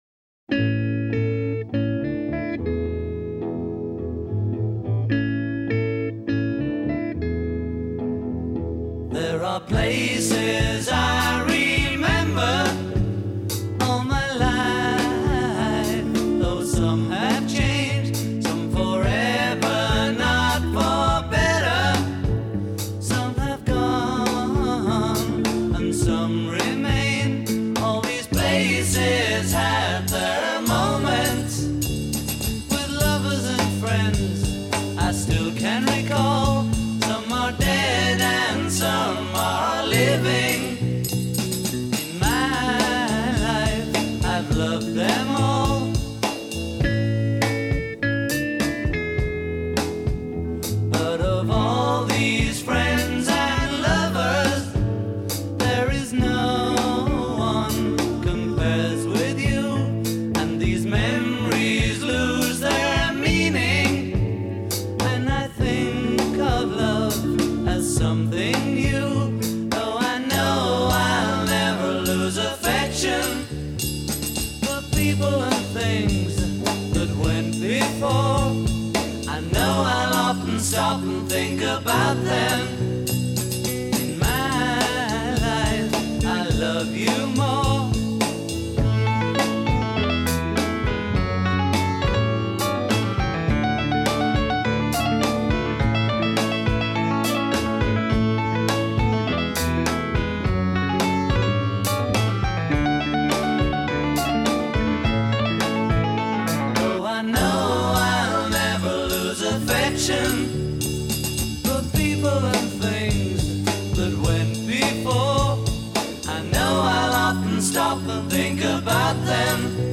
a rare harpsichord track
English rock band